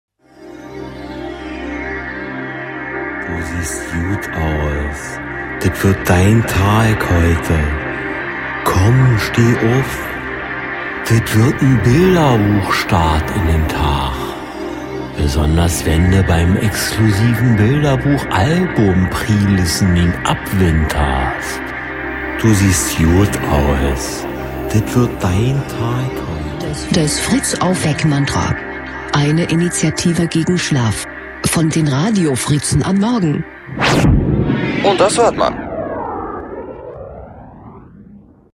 Fritz-Aufweck-Mantra 16.02.17 (Bilderbuch) | Fritz Sound Meme Jingle